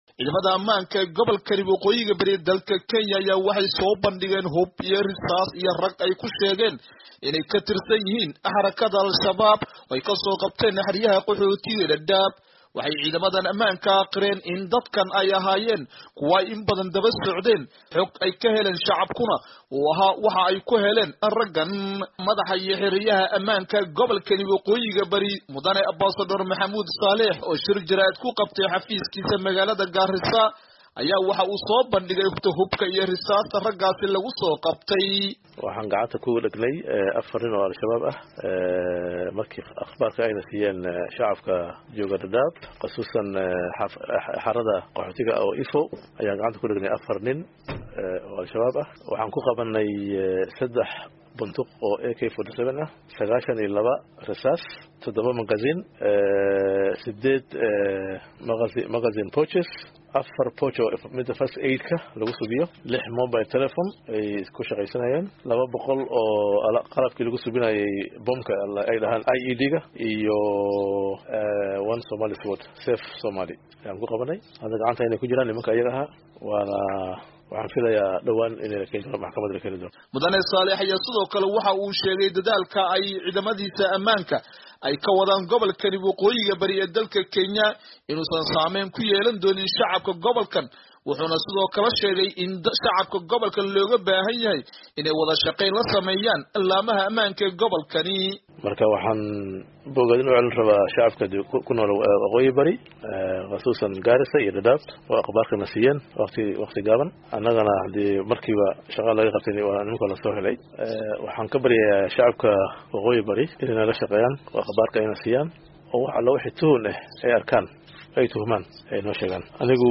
Warbixin: Hub iyo Dhallinyaro la qabtay